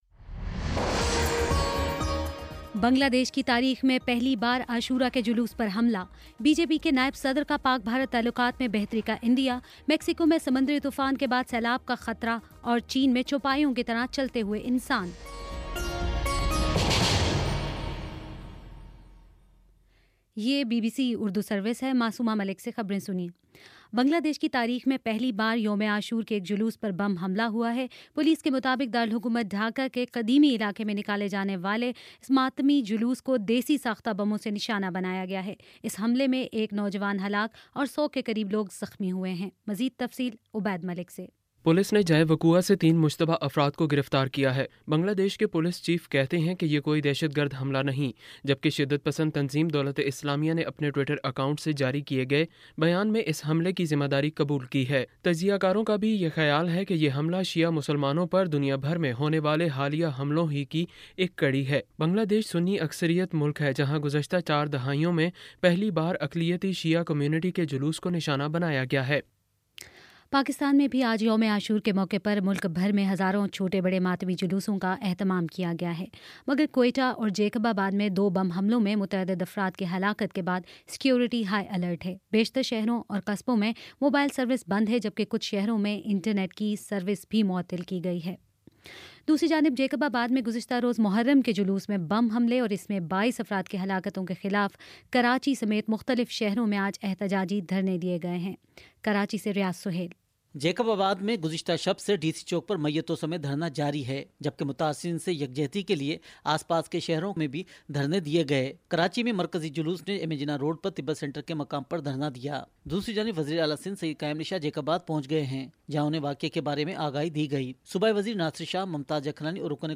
اکتوبر 24 : شام سات بجے کا نیوز بُلیٹن